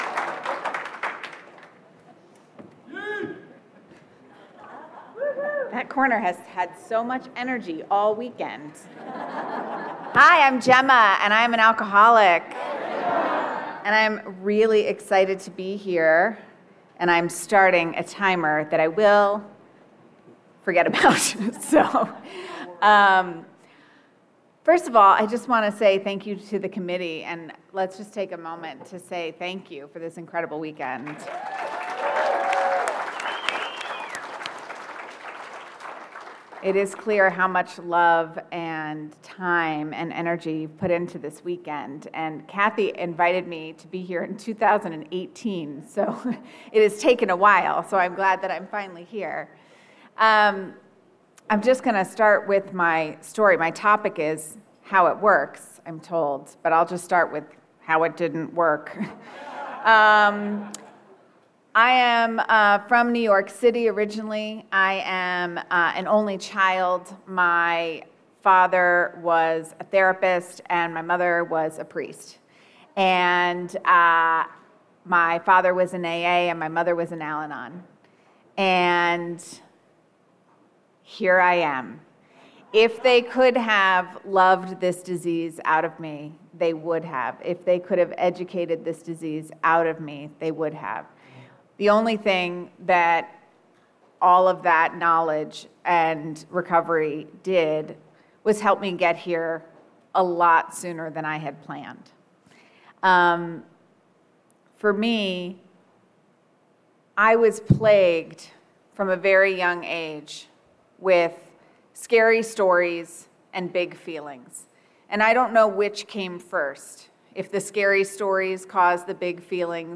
32nd Indian Wells Valley AA Roundup